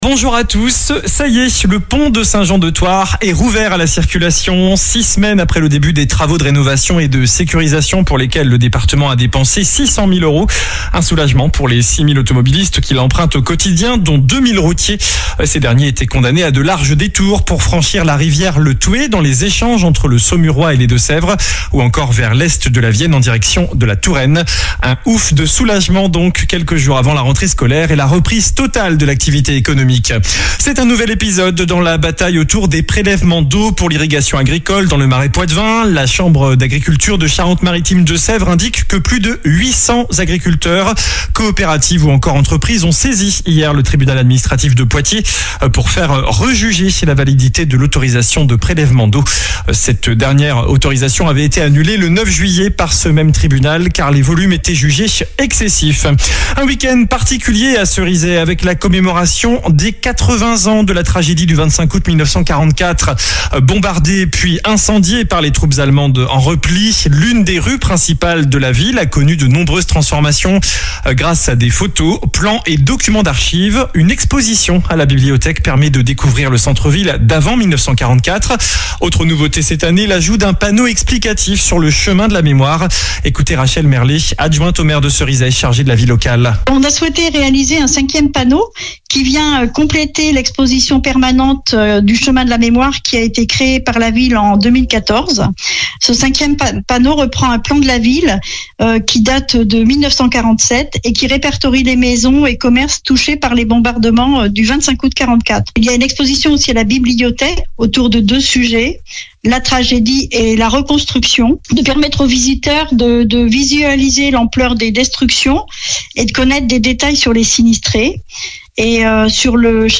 infos locales 24 août 2024